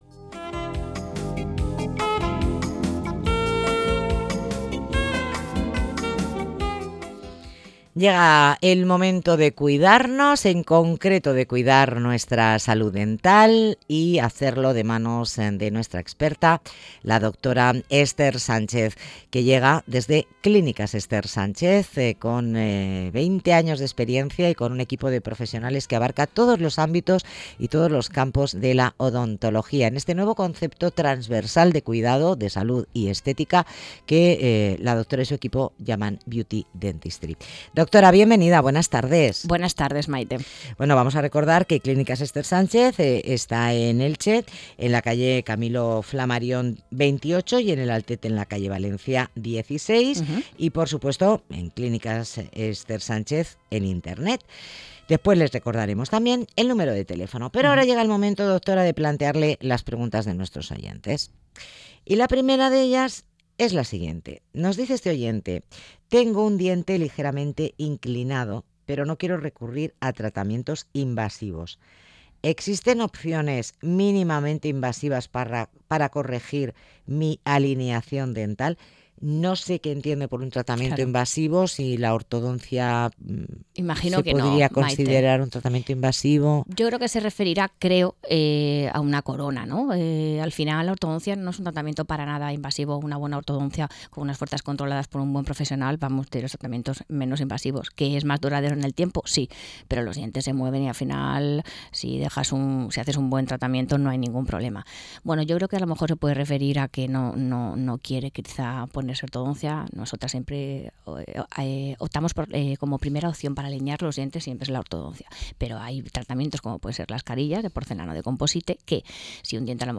consulta radiofónica